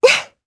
Erze-Vox_Jump_jp.wav